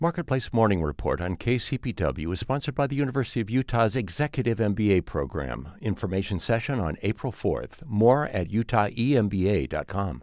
Voice Over Work